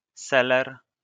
wymowa:
?/i, IPA[ˈsɛlɛr], AS[seler]